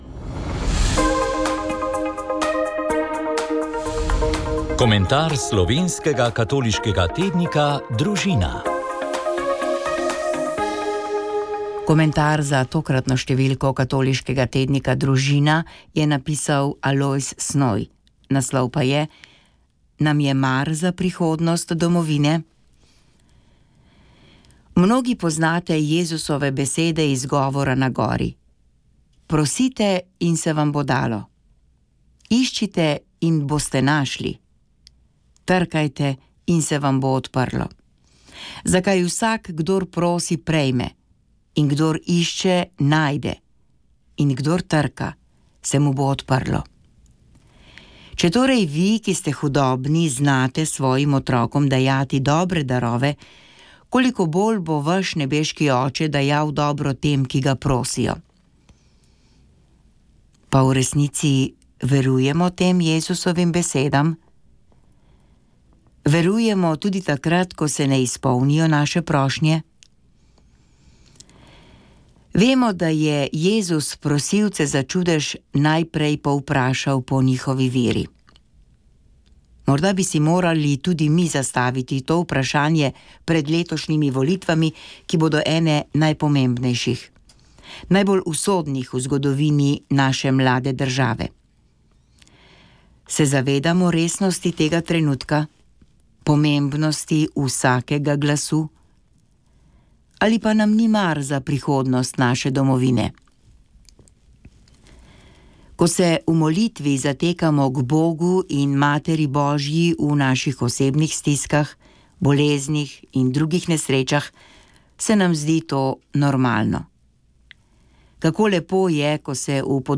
Zbrali smo nekaj odzivov svetovalcev specialistov za sadjarstvo, ki razmere po aprilski pozebi opisujejo kot katastrofo.